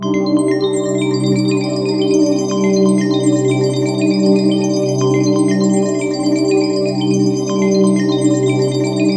SEQ PAD04.-L.wav